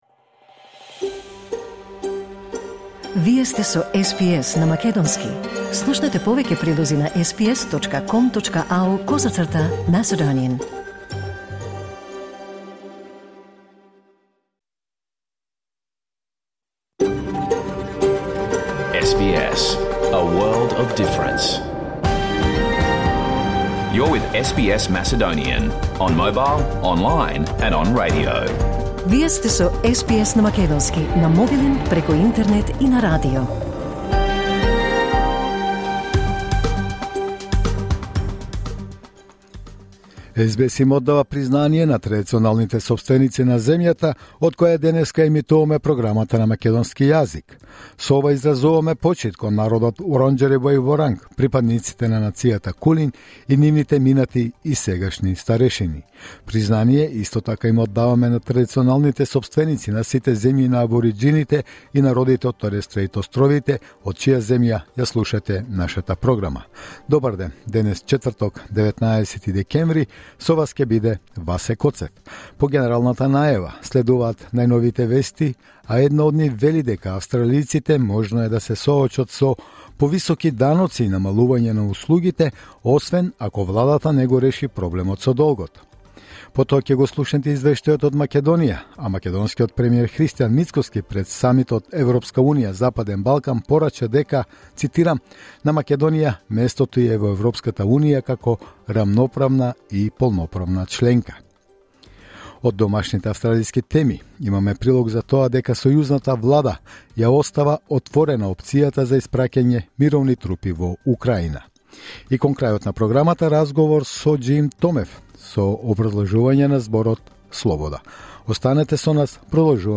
Вести на СБС на македонски 19 декември 2024